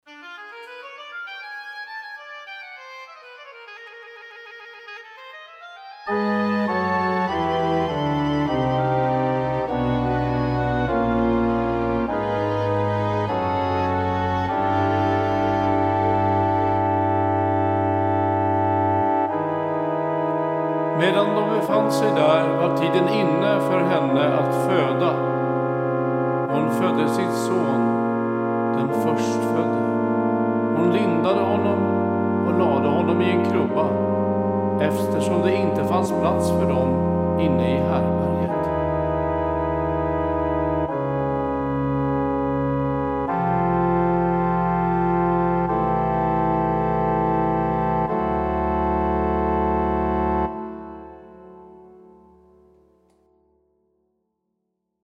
4. Andra läsningen